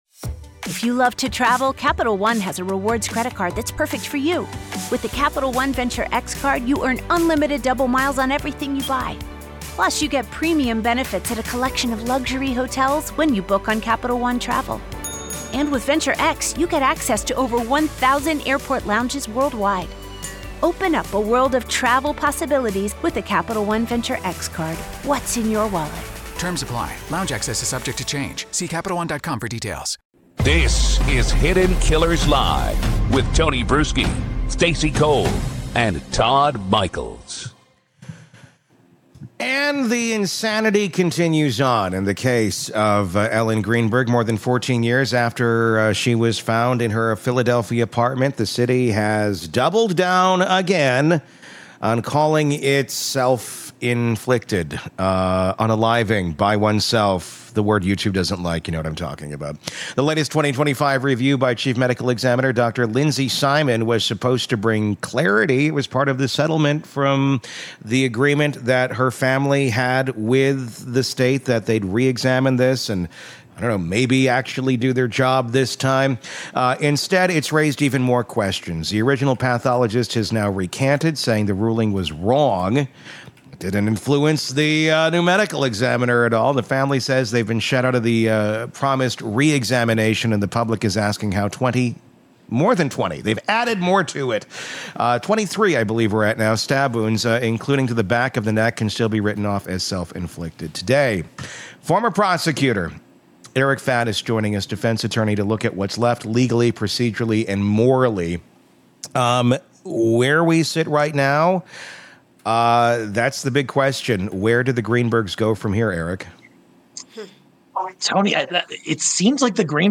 Hidden Killers Live!